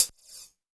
VEE Open Hihat 05.wav